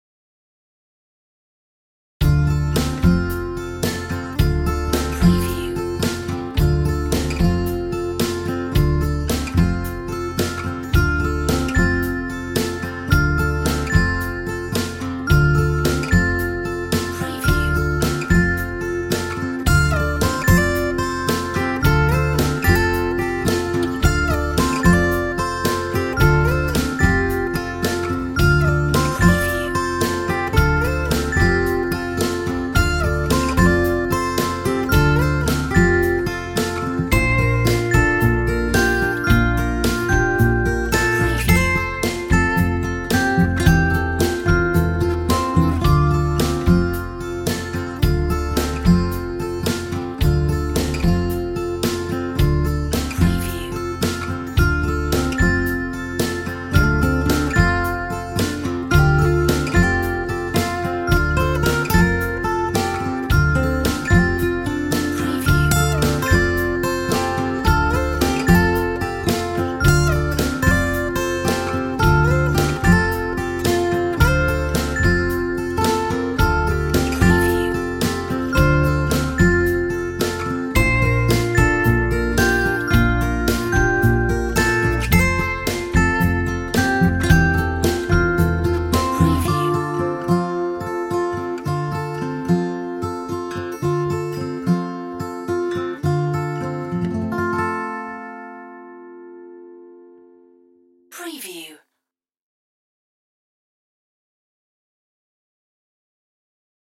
Sweet acoustic